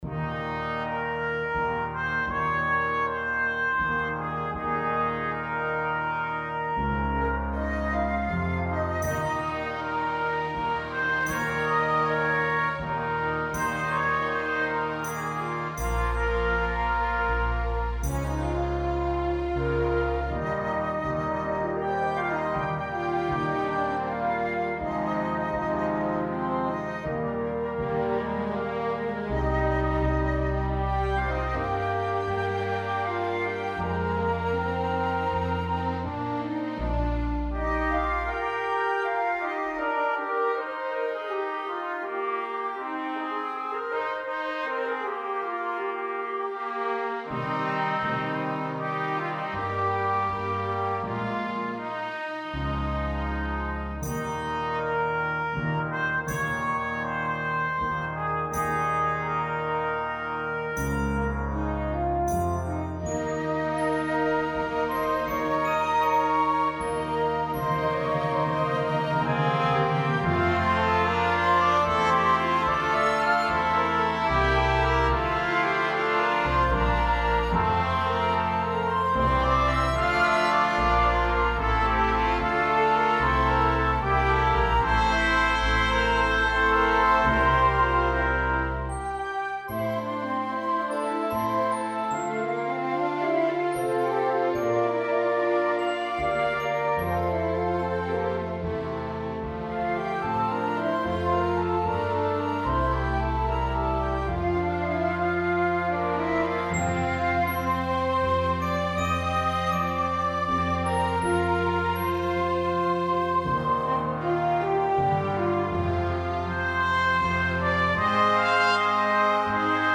Hymn arrangement
for 9 Plus orchestra – with reduced instrumentation.
Playable with as few as 9 Players.